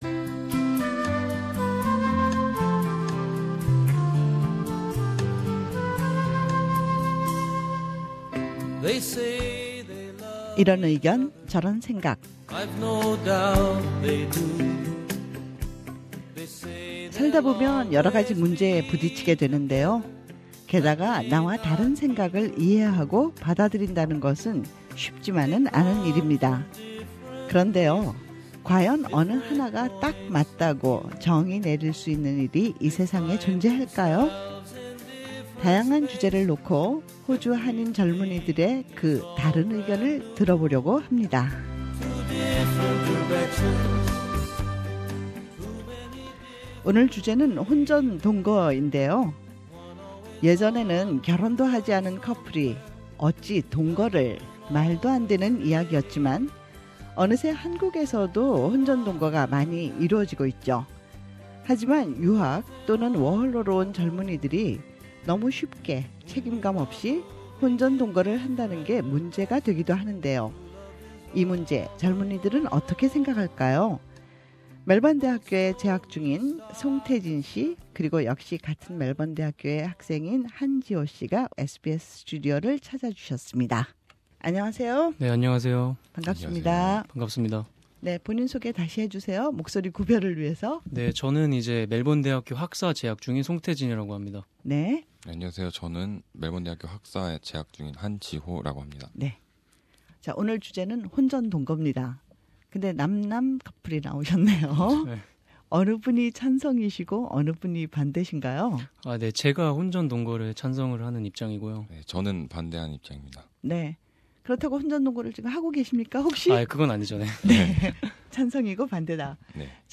우리 주변의 다양한 이슈에 대해 호주에 사는 한인 청년들은 어떤 생각을 갖고 있을까요? '이런 생각, 저런 의견' 이번주 토론 주제는 '결혼 전 동거 생활'입니다.